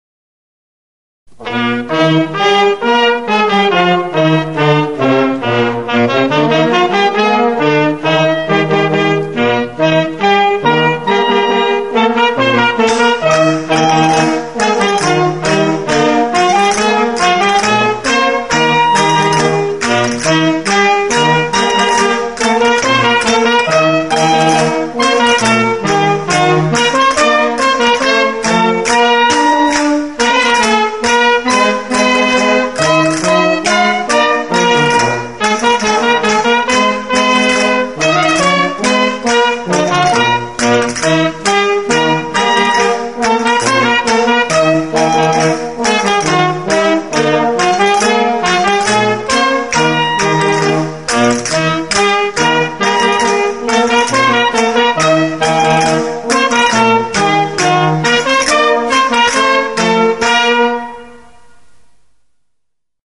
Bolero_2.mp3